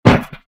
DHL BEAT SWITCH SNARE .mp3